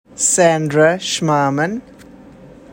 Education and training Residency Diagnostic radiology-Long Island College Hospital, Brooklyn, New York Fellowship Musculoskeletal imaging-Hospital for Joint Diseases, New York Additional information Name pronunciation